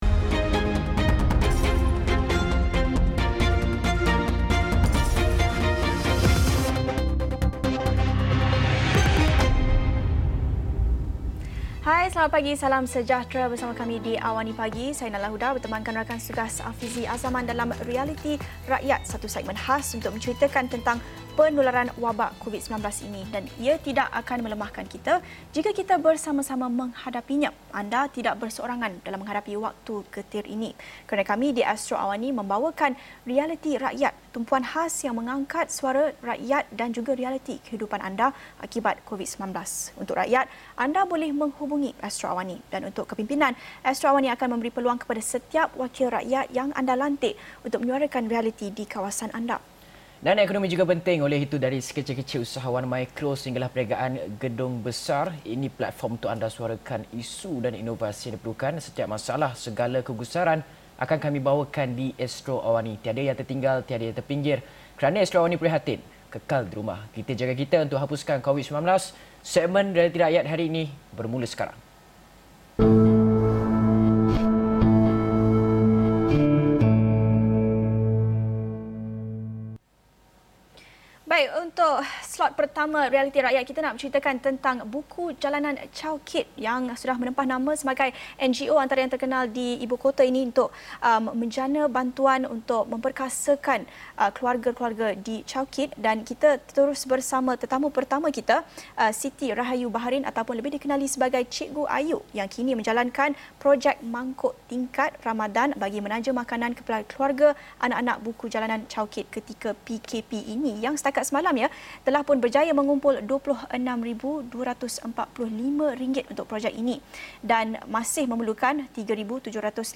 Episod ini telah disiarkan secara langsung dalam program AWANI Pagi, di saluran 501, jam 8:30 pagi.